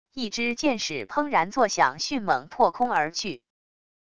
一枝箭矢砰然作响迅猛破空而去wav音频